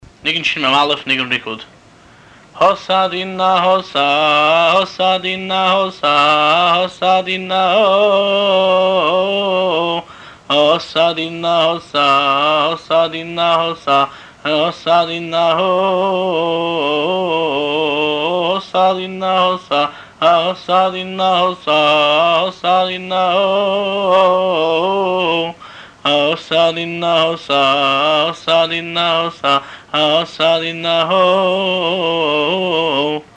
האסא דינא האסא הינו ניגון עליז וצוהל אותו שרים בזמני שמחה מיוחדים כמו שמחת תורה ופורים. את הניגון כופלים וחוזרים וכופלים פעמים רבות ובכל פעם בהתלהבות יתירה.